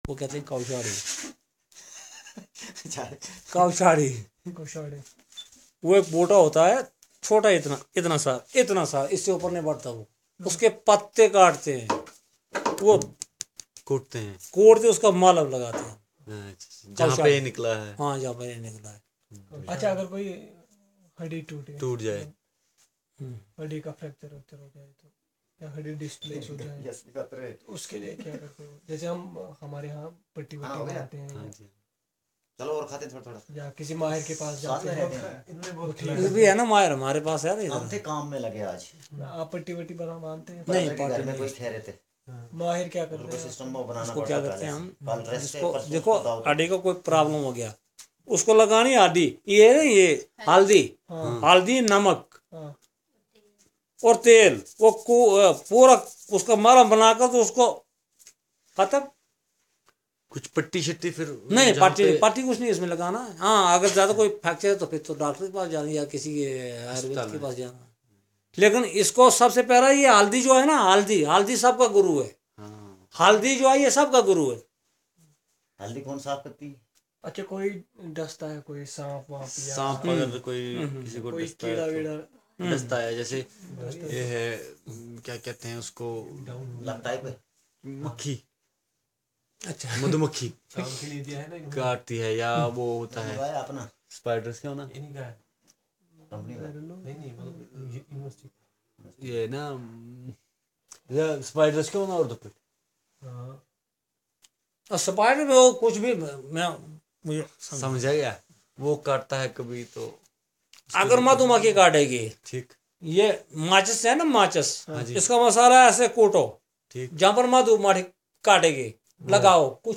Conversation about medicines - Part 1